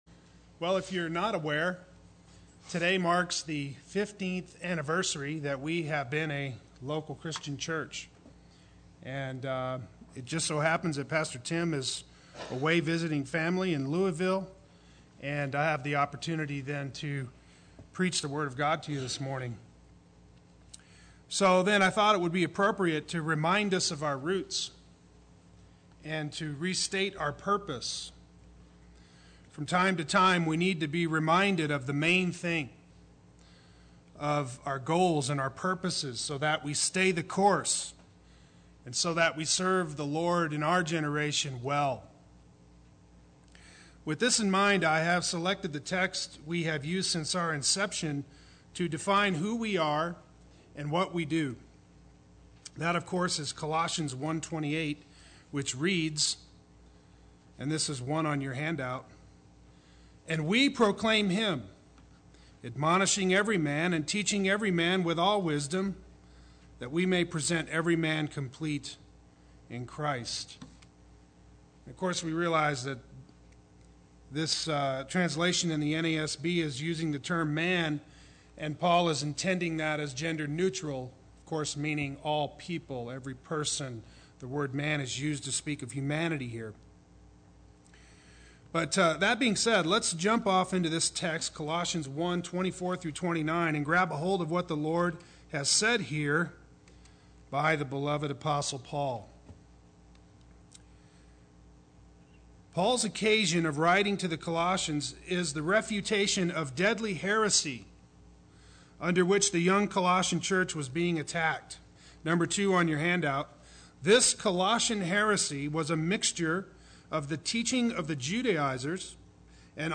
Play Sermon Get HCF Teaching Automatically.
We Proclaim Him Sunday Worship